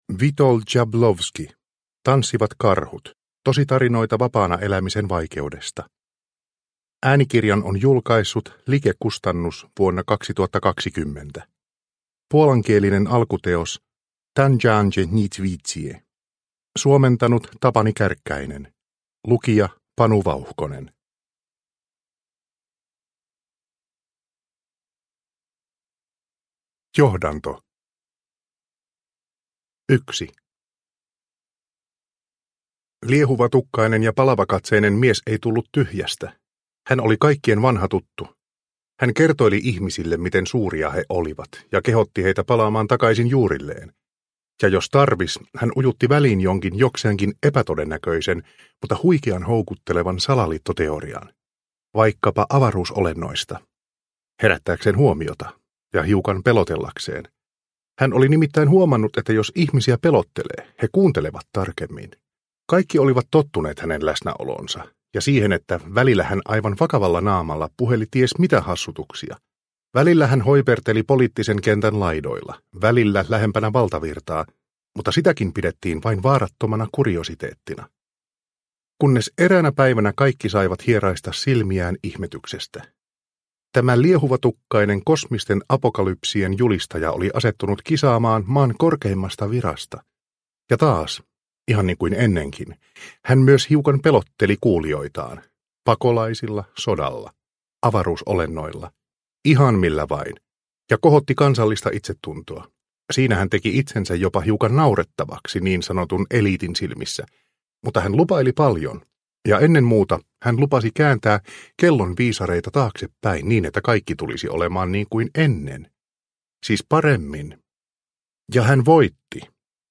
Tanssivat karhut – Ljudbok – Laddas ner